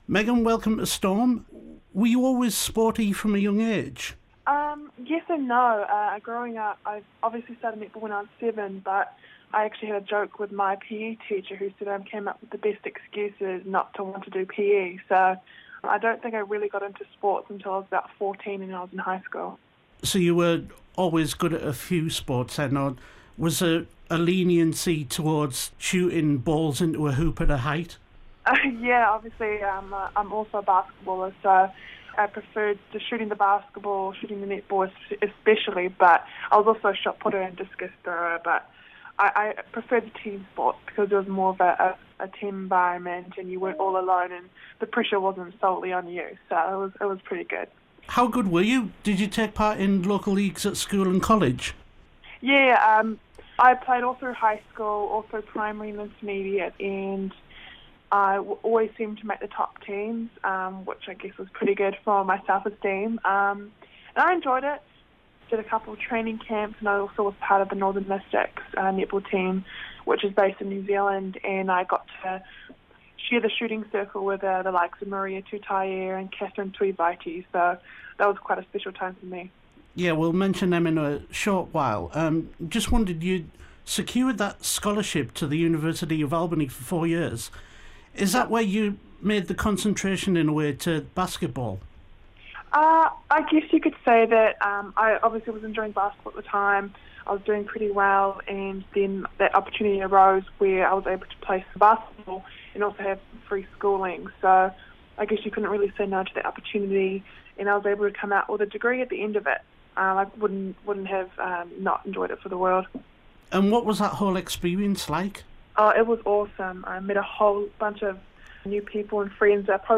Here's her first UK interview after signing for Surrey Storm ahead of the 2017 Netball Super League season